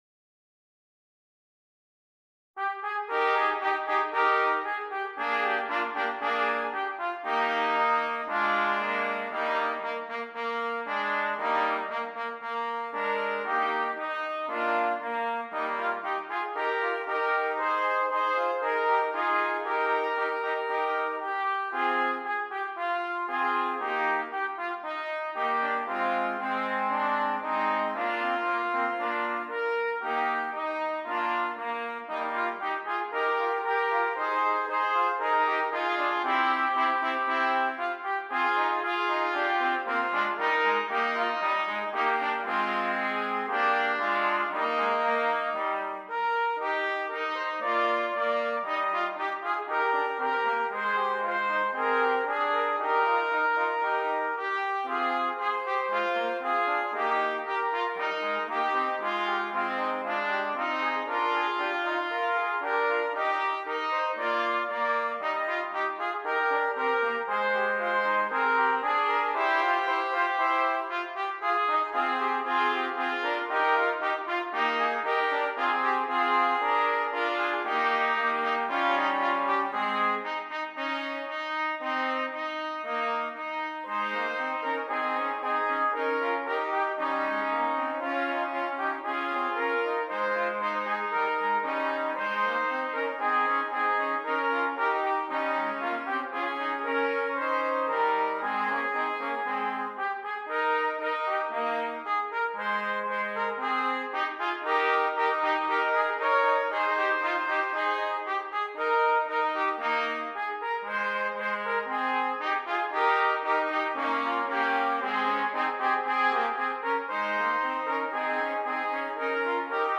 Brass
3 Trumpets